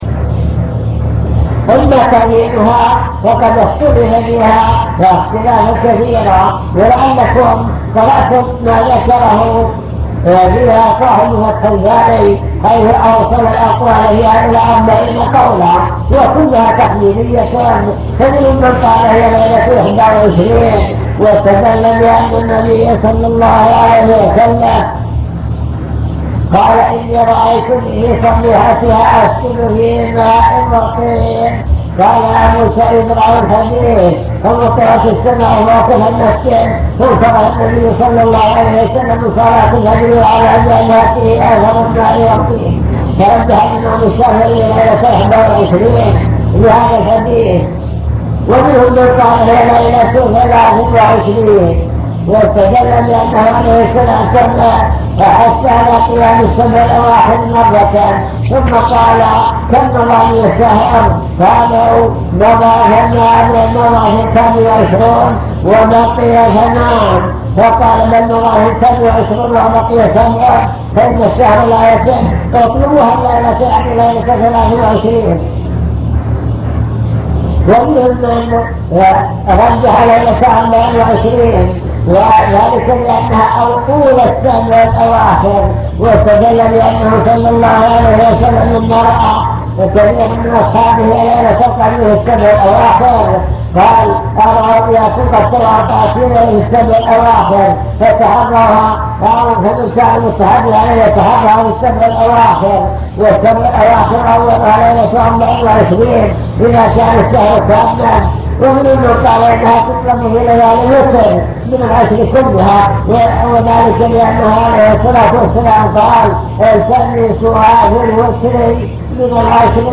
المكتبة الصوتية  تسجيلات - محاضرات ودروس  مجموعة محاضرات ودروس عن رمضان ليلة القدر